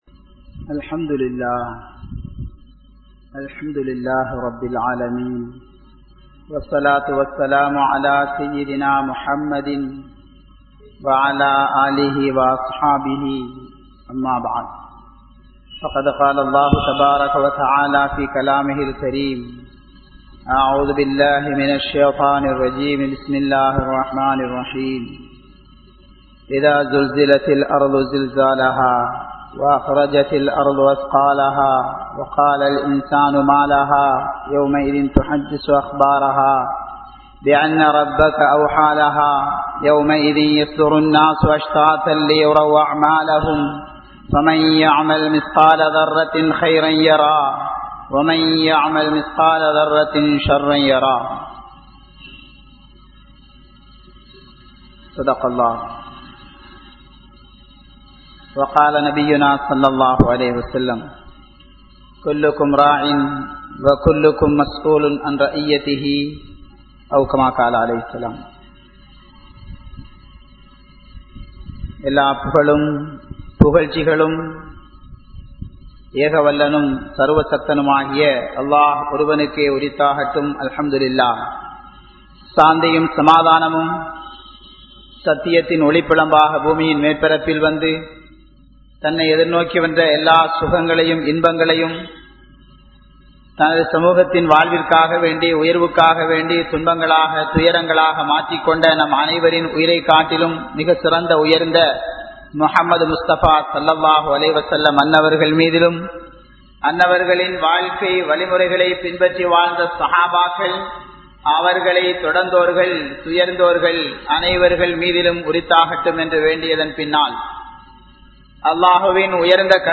Qiyamath Naalin Adaiyaalangal (கியாமத் நாளின் அடையாளங்கள்) | Audio Bayans | All Ceylon Muslim Youth Community | Addalaichenai